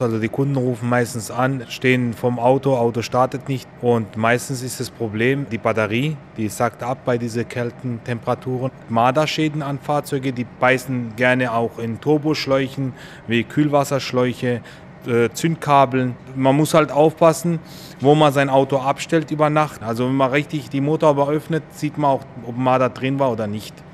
Ständig klingelt das Telefon, sagt er im SWR-Interview.